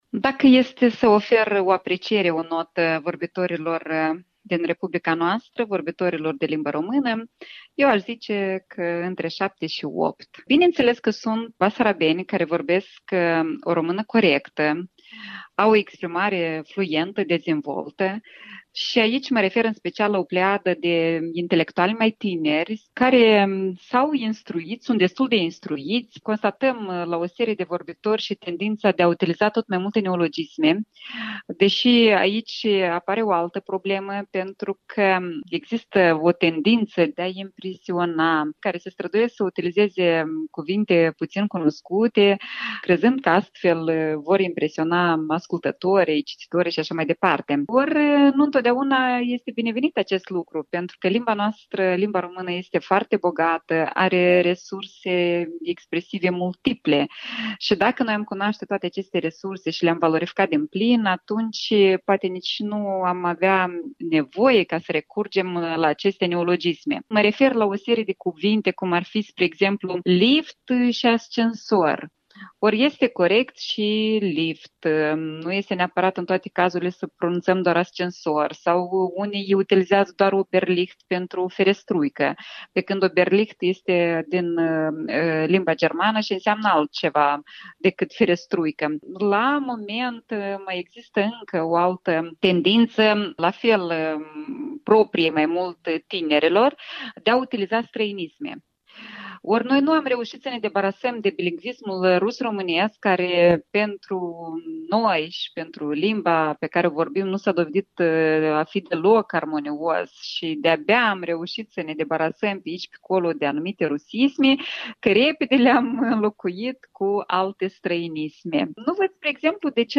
Interviul dimineții la EL